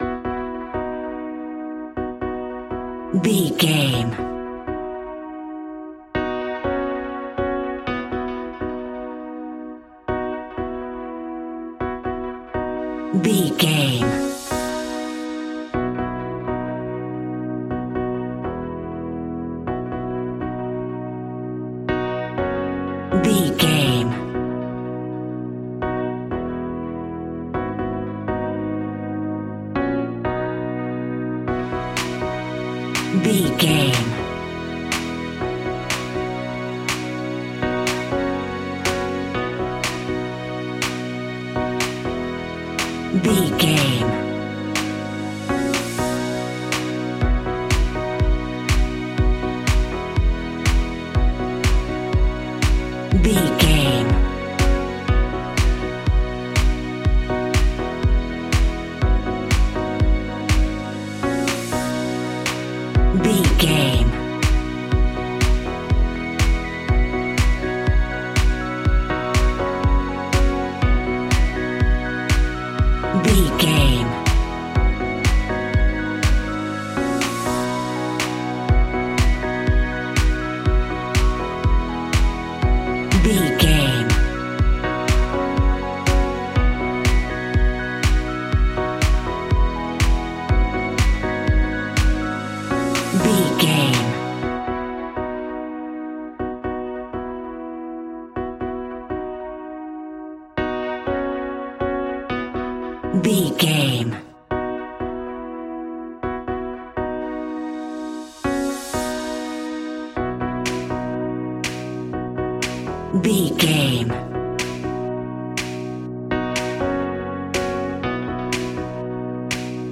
Ionian/Major
groovy
uplifting
driving
energetic
cheerful/happy
repetitive
bouncy
synthesiser
drum machine
strings
electric piano
electronic
dance
synth leads
synth bass